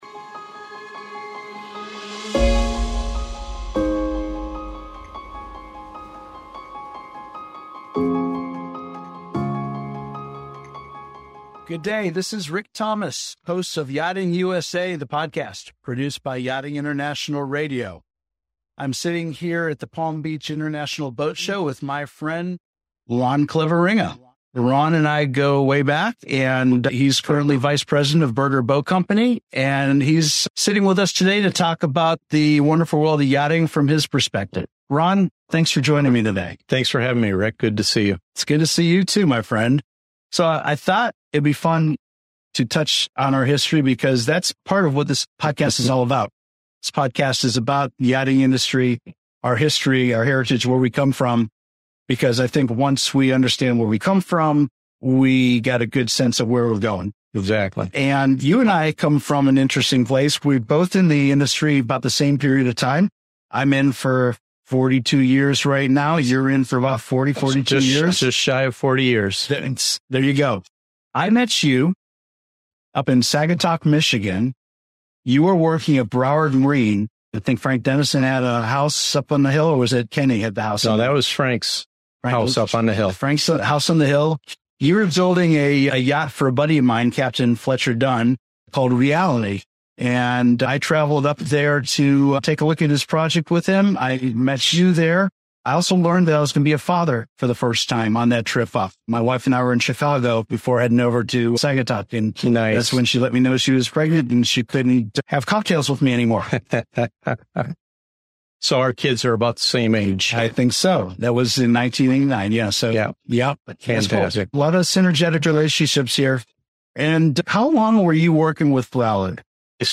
Gain insights into trends, challenges, and the evolving dynamics of yacht ownership and building. Don't miss this insightful conversation with two industry veterans shaping the future of North American yachting.